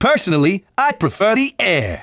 Falco_voice_sample_SSBB.oga